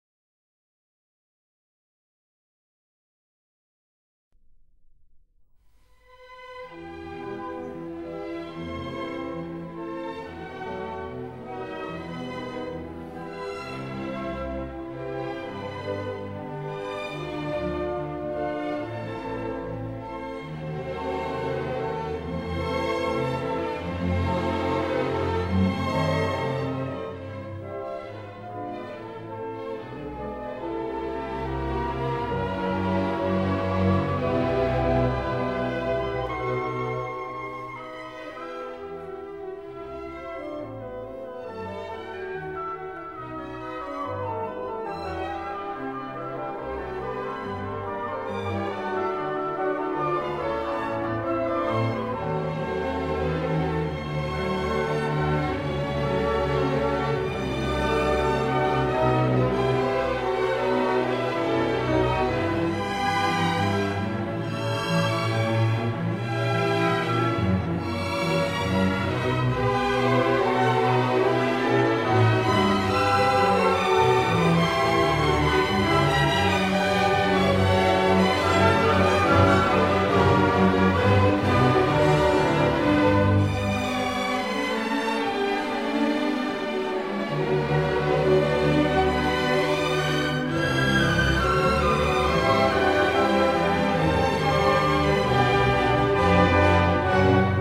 * Johannes Brahms – Symphony no. 4 in E minor (First movement)